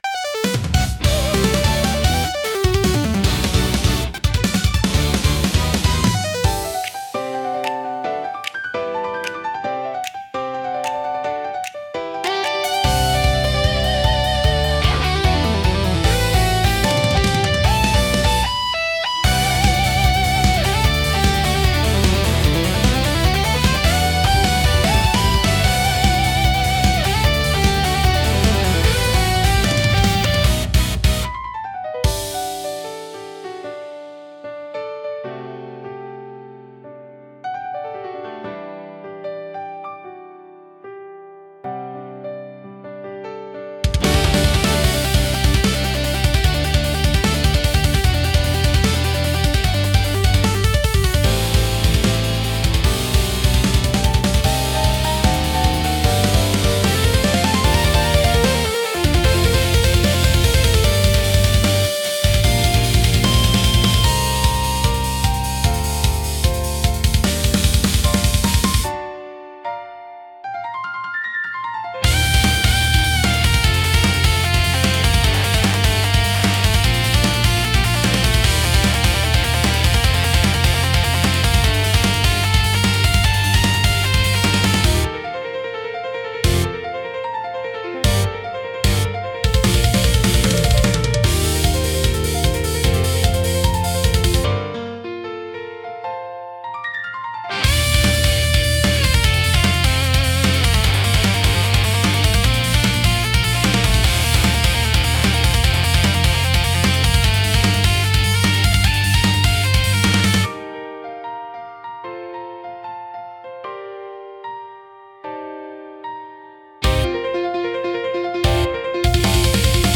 テンポの速いリズムと洗練されたサウンドで、スリルと爽快感を演出し、没入感を促進します。